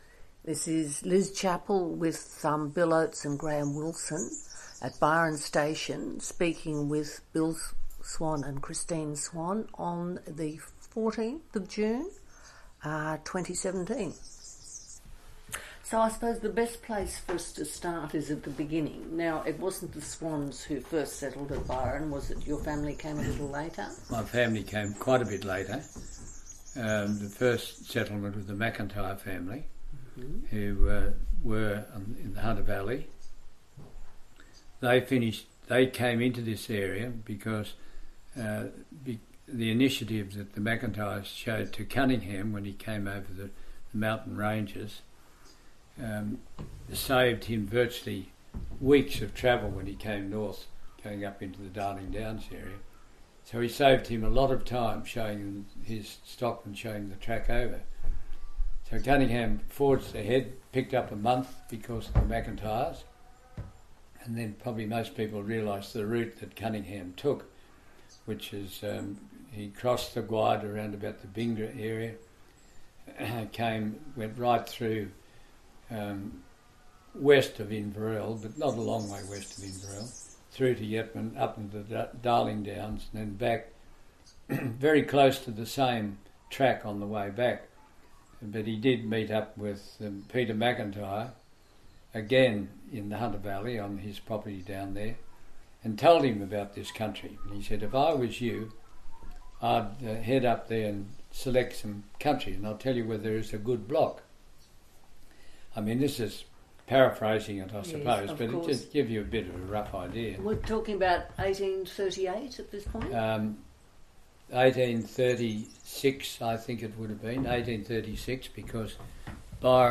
Interview Recording